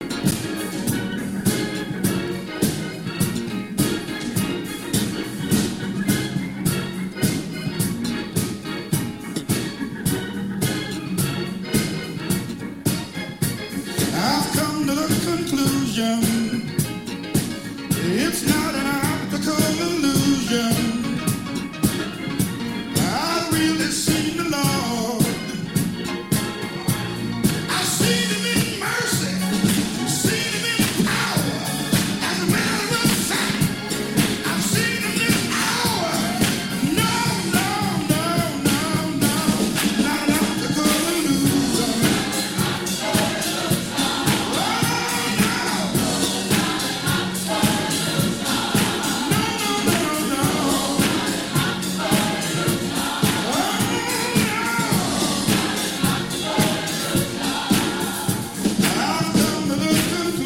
Gospel Disco.